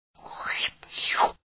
2_lash.mp3